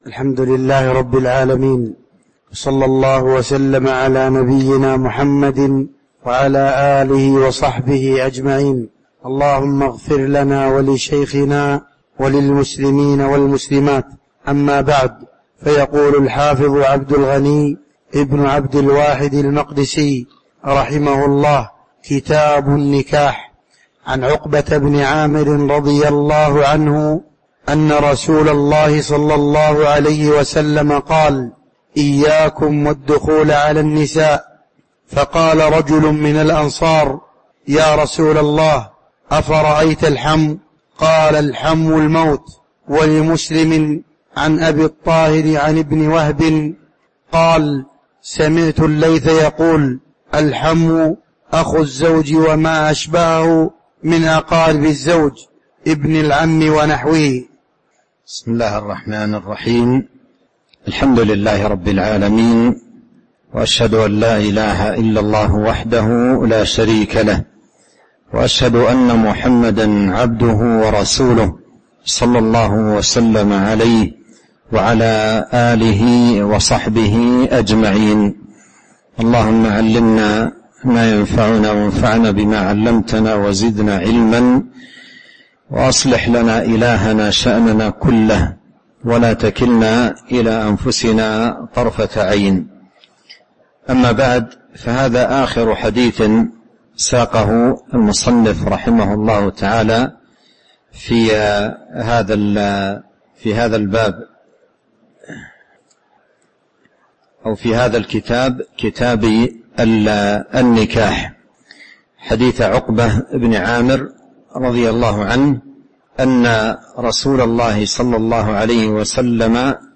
تاريخ النشر ١٥ رجب ١٤٤٤ هـ المكان: المسجد النبوي الشيخ